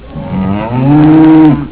Mmooooo !